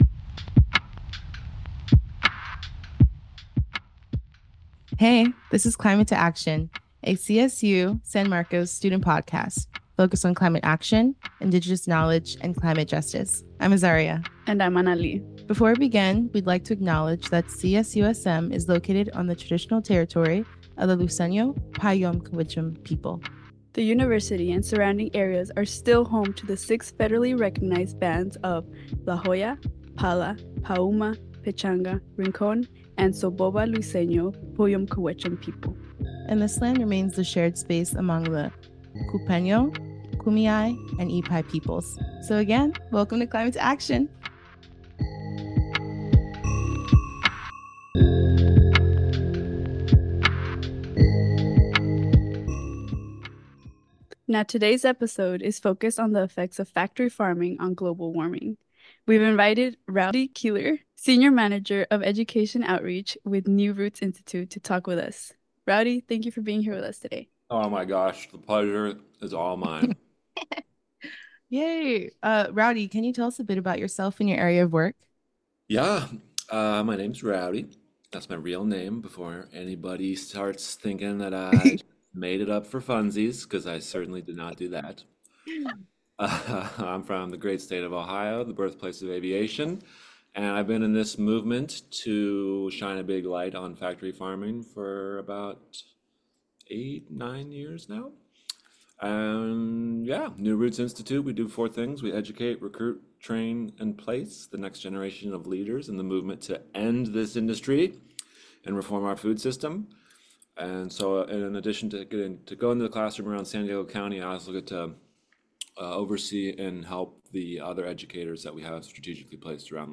Climate To Action is produced at the CSUSM Inspiration Studios.